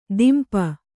♪ dimpa